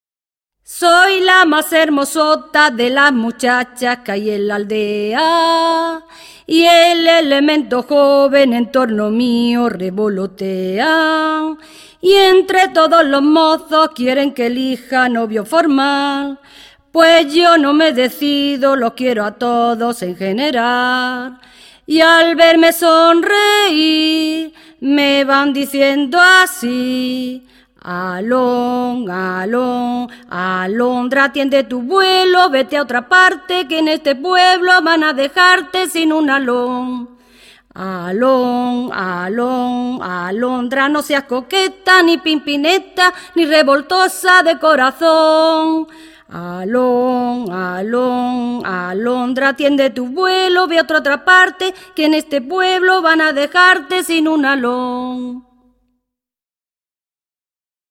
Pura : op. 20 : mazurca para salón